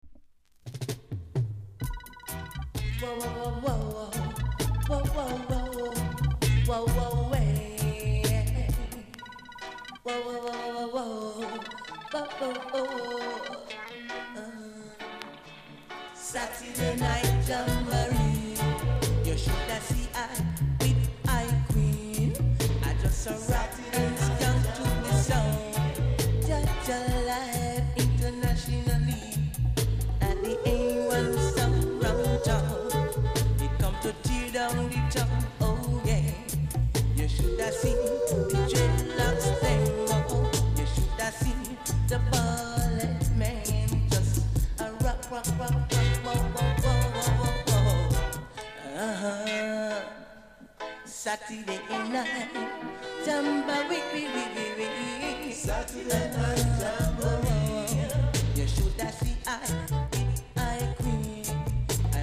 ※小さなチリノイズが少しあります。
コメント DEEP 80's!!!YELLOW WAX